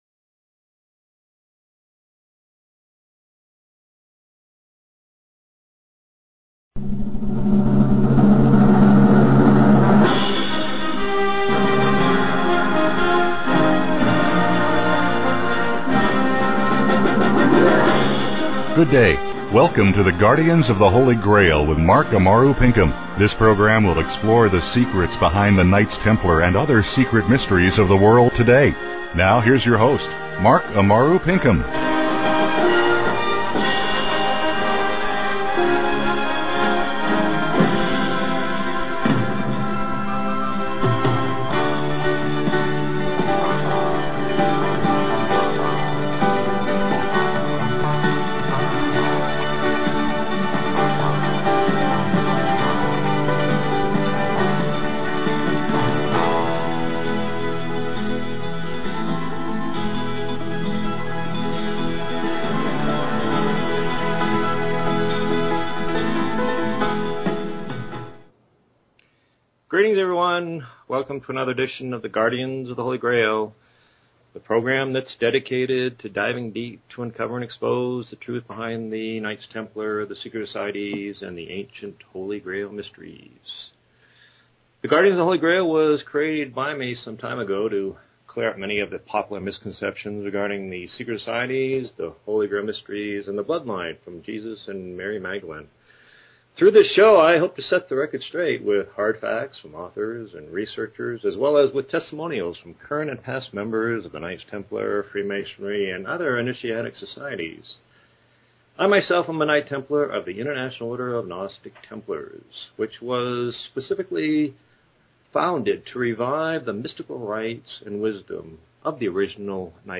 Talk Show Episode, Audio Podcast, The_Guardians_of_the_Holy_Grail and Courtesy of BBS Radio on , show guests , about , categorized as